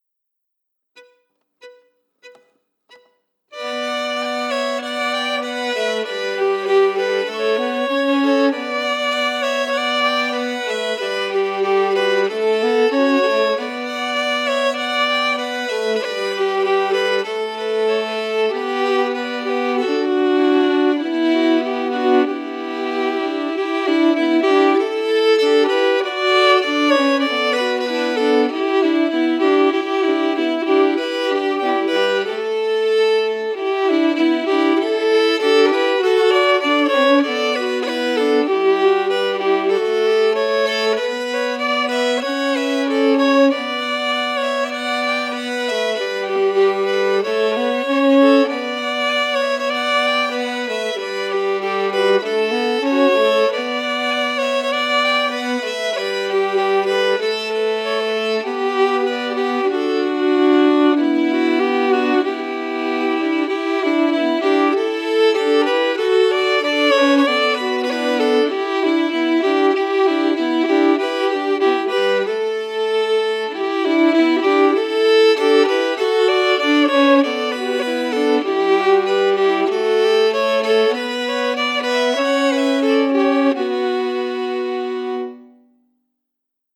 Key: Bm
Form: Reel
Harmony emphasis